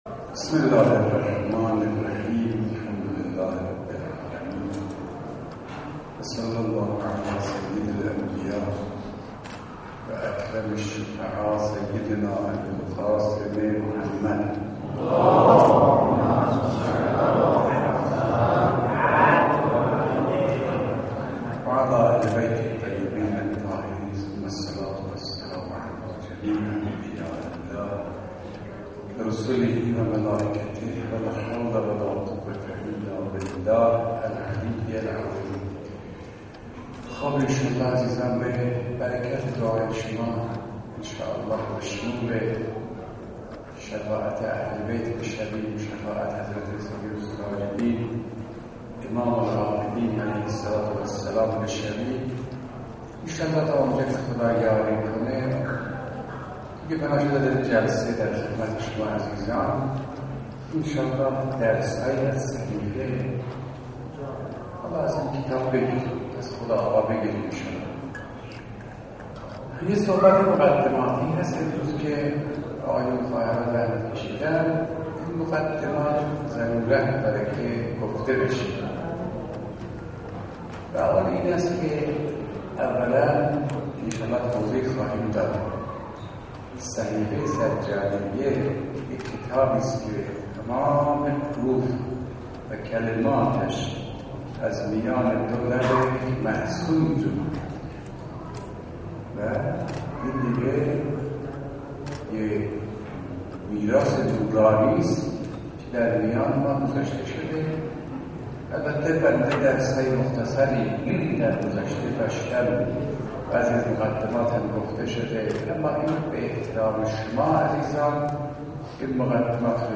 صوت/سیری در صحیفه سجادیه با سخنرانی استاد فاطمی نیا
جلسه تفسیر صحیفه سجادیه توسط استاد سید عبدالله فاطمی نیا در مسجد جامع ازگل برگزار شد.